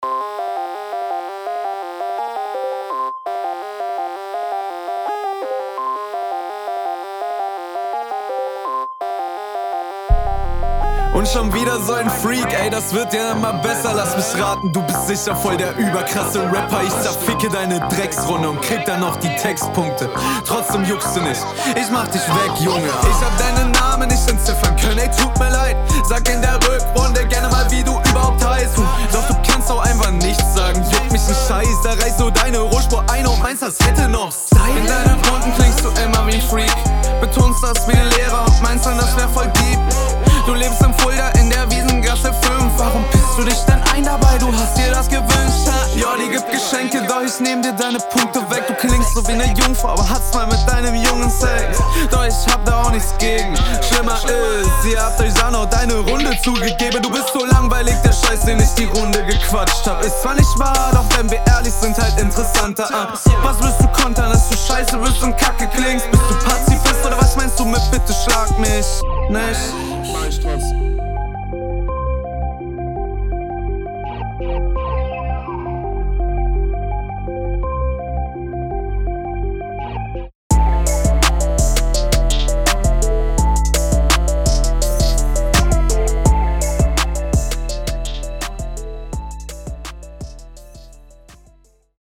Flow kommt richtig chillig.